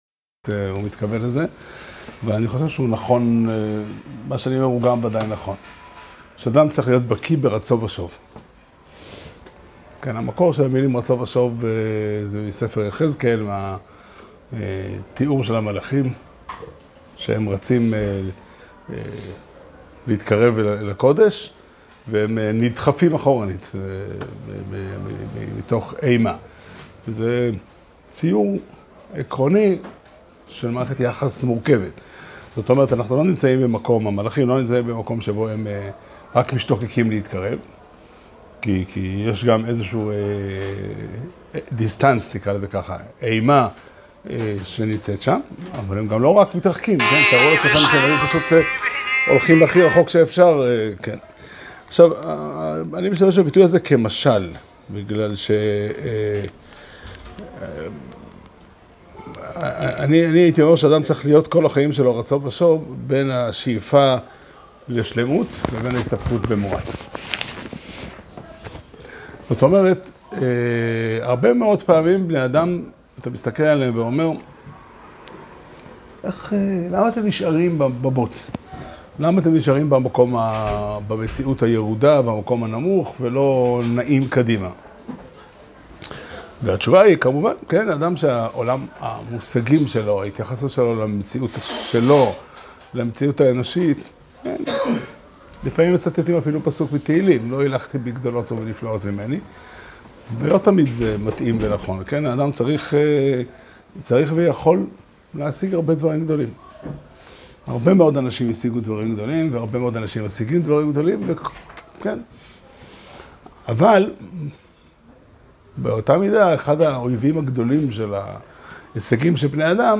שיעור שנמסר בבית המדרש פתחי עולם בתאריך כ"ח טבת תשפ"ה